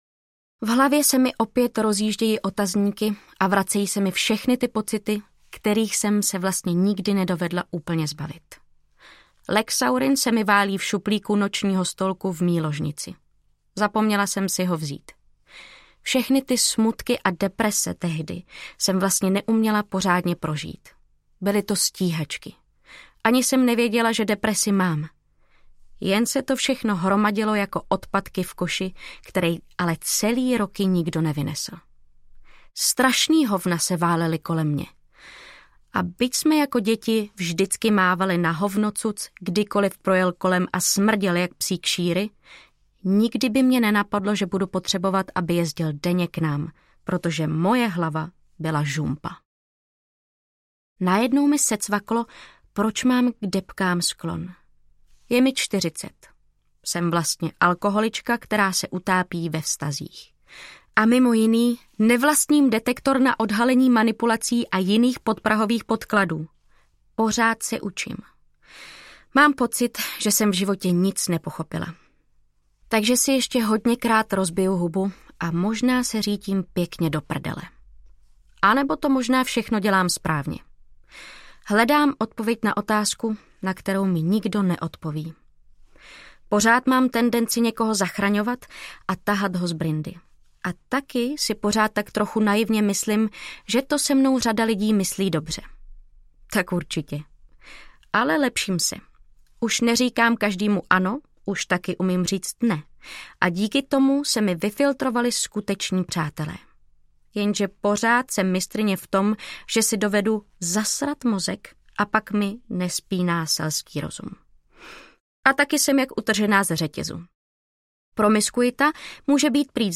MP3 Audiobook
Audiobooks » Social Fiction